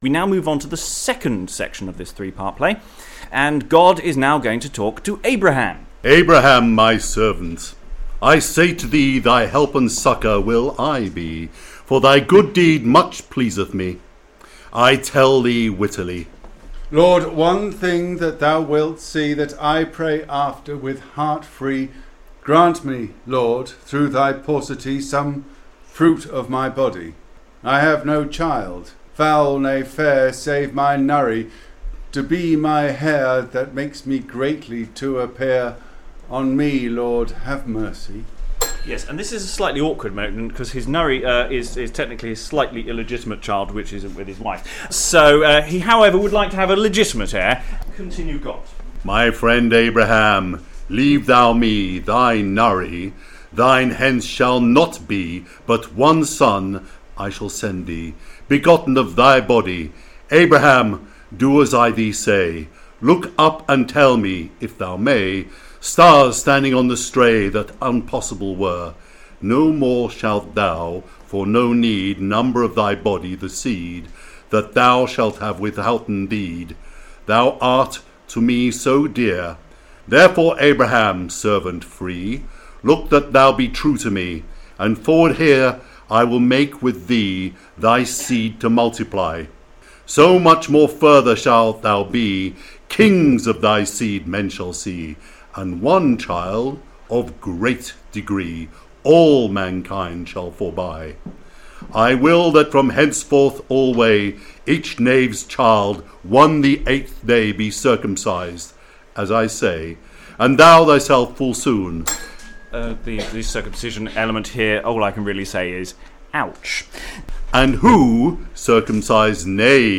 Exploring the Chester Mystery Plays is a series of live streamed events where the Chester plays are taken apart with readers and commentary. Rough round the edges, edited versions of these events are now being posted online. This post covers the middle section of play 4, where Abraham asks God for a legitimate child and where God asks him to then kill that child - Isaac.